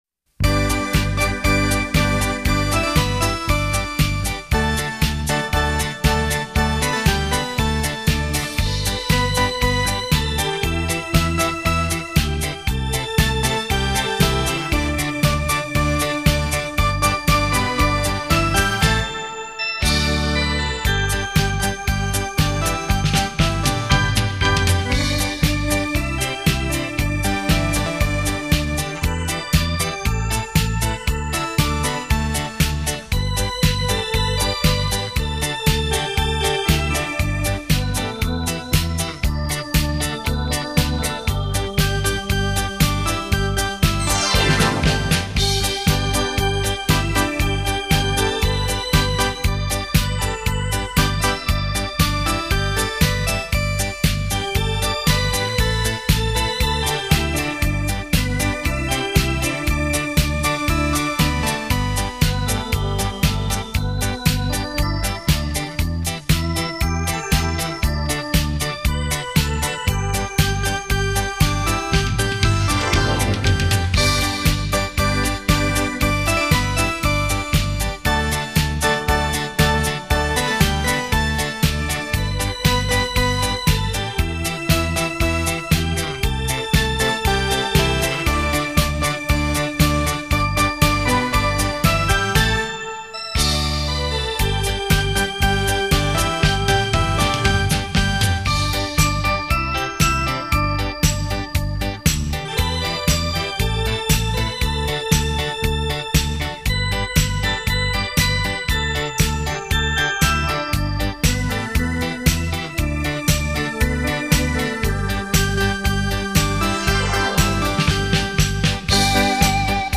专辑类型：电子琴音乐
一张录音极为精彩的电子琴音乐。
电音电子技术融入到纯美的音乐当中,  旋律美妙、 独具韵味 。